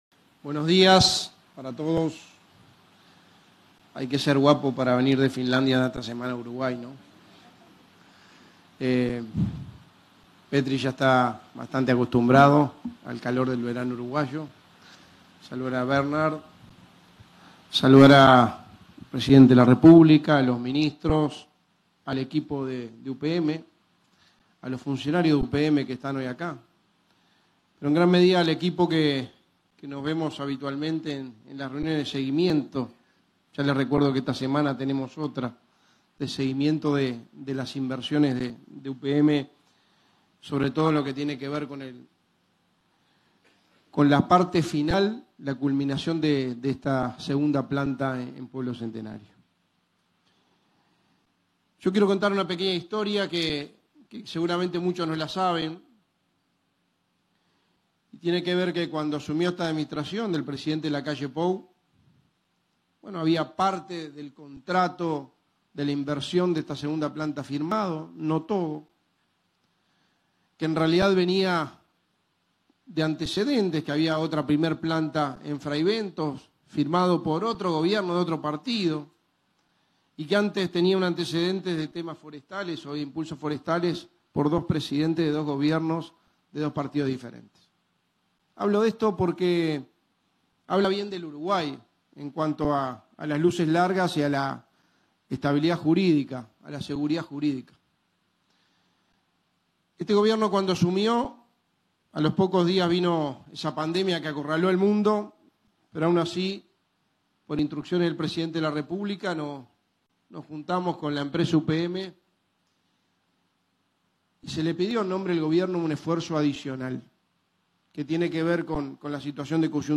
Palabras del secretario de Presidencia de la República, Álvaro Delgado
Con motivo de la inauguración del vivero UPM en Sarandí del Yí, este 13 de febrero, se expresó el secretario de la Presidencia, Álvaro Delgado.